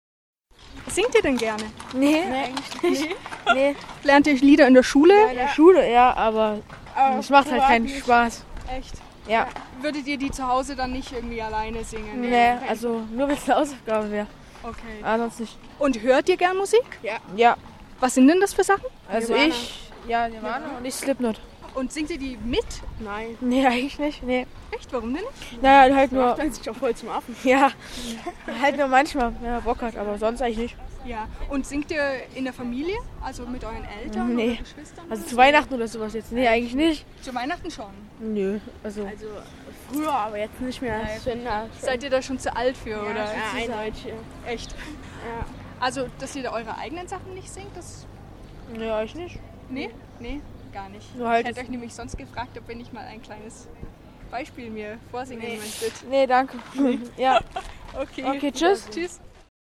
she recorded hundreds of people singing a song of their choice and offered a drawing as a currency.